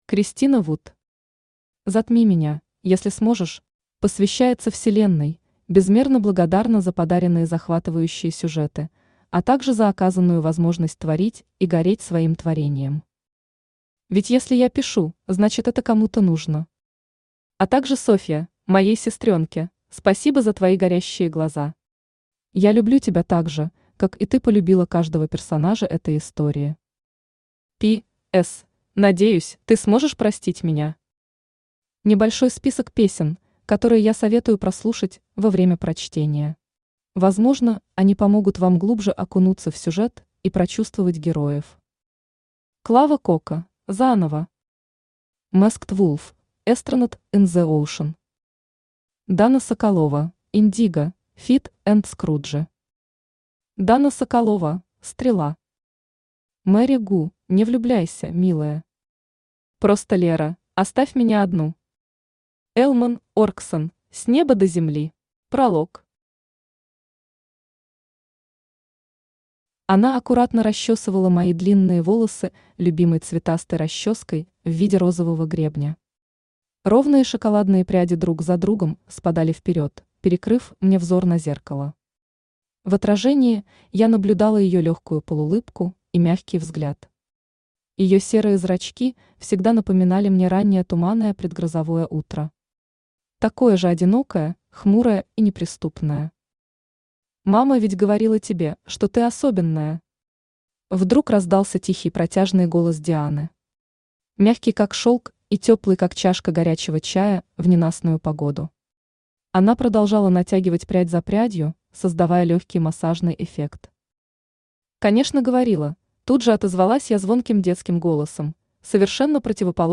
Аудиокнига Затми меня, если сможешь | Библиотека аудиокниг
Aудиокнига Затми меня, если сможешь Автор Кристина Вуд Читает аудиокнигу Авточтец ЛитРес.